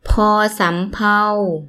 – poor ∨ samm – pau
poor-samm-pau.mp3